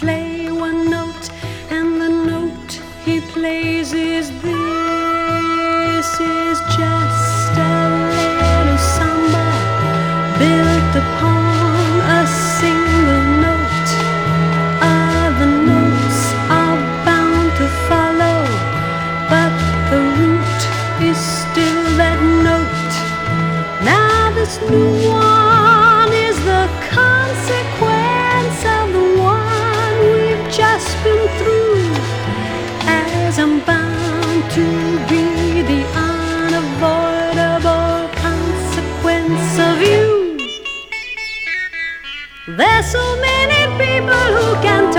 民族音楽や世界の楽器、最新の電子楽器も、貪欲にぶち込んでミックス。
Pop, Stage&Screen, Experimemtal　USA　12inchレコード　33rpm　Stereo